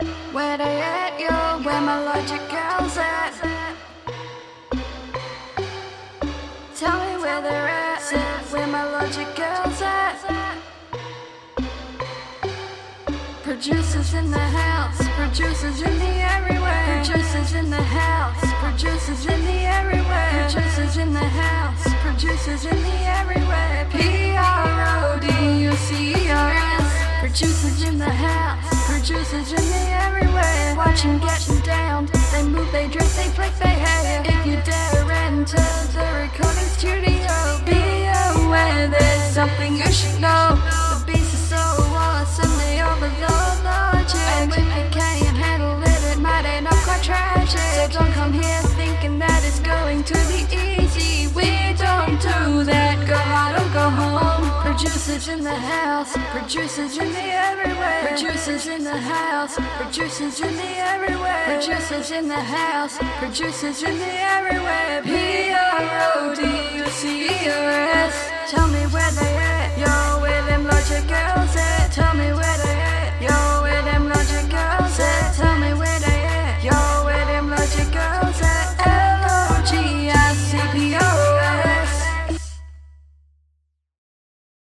This is not entitled to offend anyone, just me havin a fun jam and creating a rebel song.